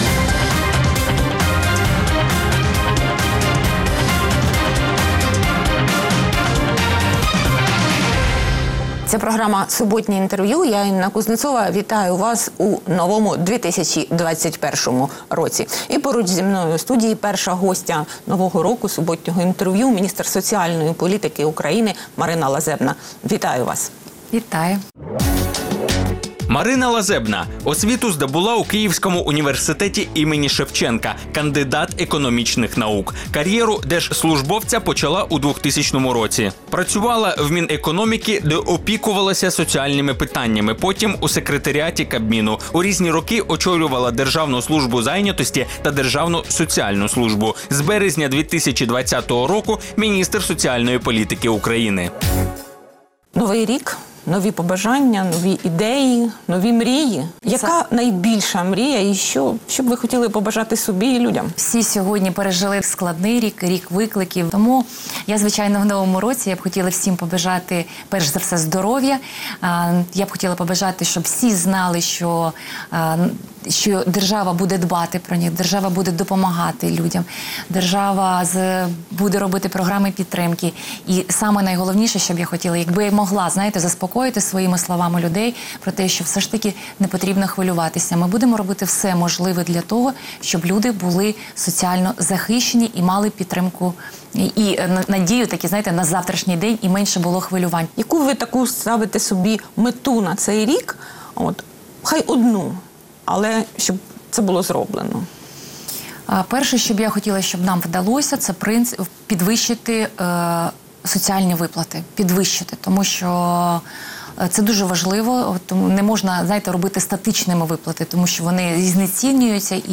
Суботнє інтерв’ю | Марина Лазебна, міністр соціальної політики України
Суботнє інтвер’ю - розмова про актуальні проблеми тижня. Гість відповідає, в першу чергу, на запитання друзів Радіо Свобода у Фейсбуці